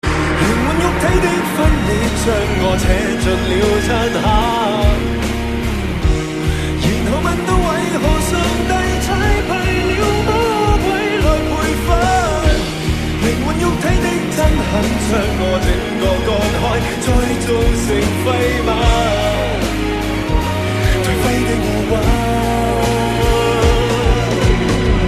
M4R铃声, MP3铃声, 华语歌曲 105 首发日期：2018-05-15 10:40 星期二